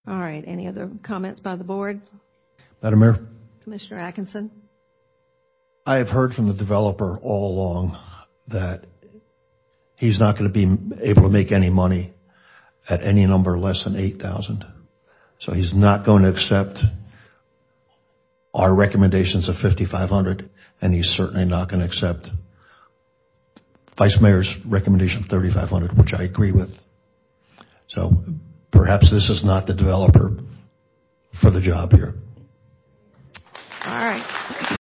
A chamber nearly full of residents with nary a Reserve supporter erupted in applause when Commissioners John Rogers and David Atkinson set down their markers in opposition near the beginning of the discussion in fierce language that suggested disbelief that the proposal had gotten that far.
atkinson-clip.mp3